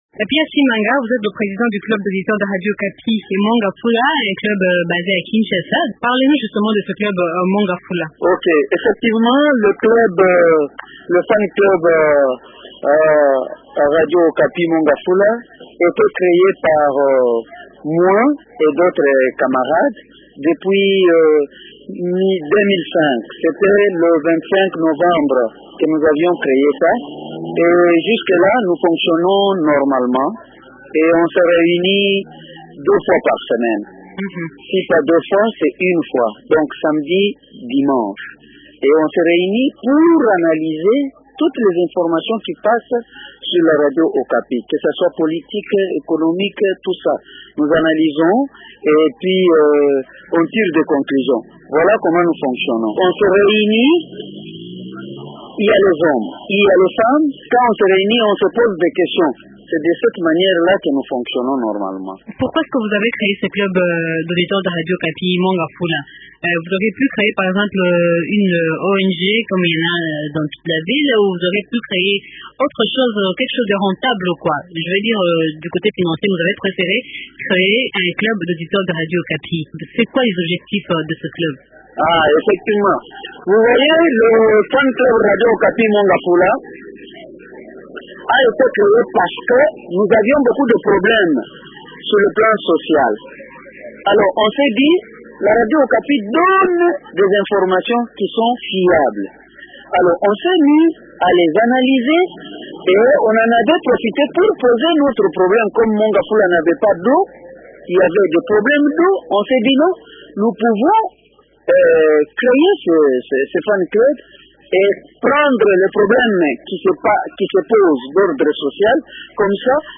4e anniversaire de la Radio Okapi avec le Caro Mont Ngafula